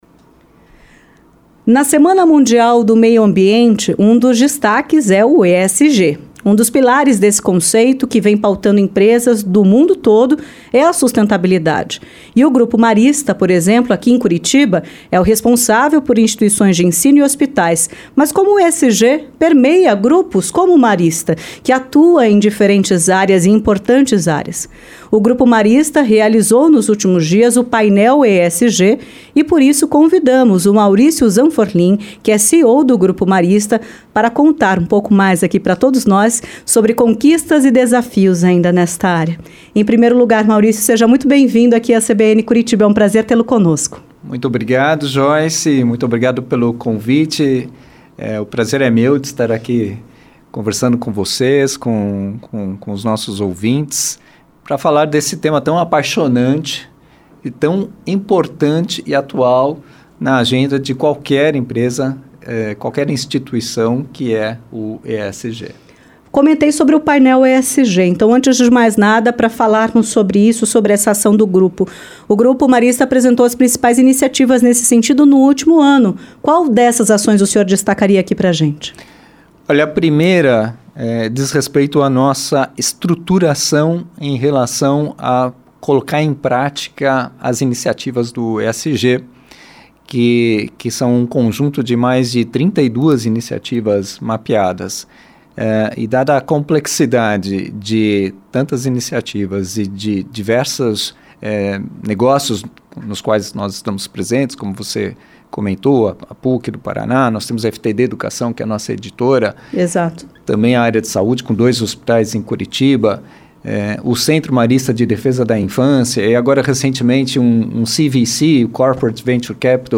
ENTREVISTA-GRUPO-MARISTA-PARA-SEGUNDA-DIA-09.mp3